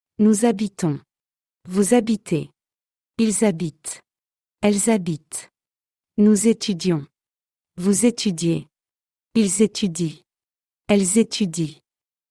**Attention : quand « nous, vous, ils et elles » sont suivis d'une voyelle ou d'un « h » muet, on fait la liaison : nous_habitons, vous_habitez, ils_habitent, elles_habitent, nous_étudions, vous_étudiez, ils_étudient, elles_étudient...
Nous_habitons.mp3